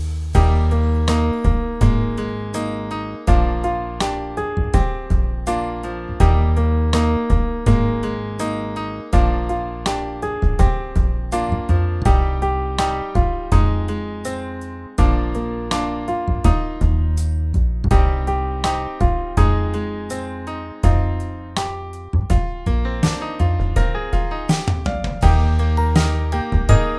utwór w wersji instrumentalnej
Patriotyczne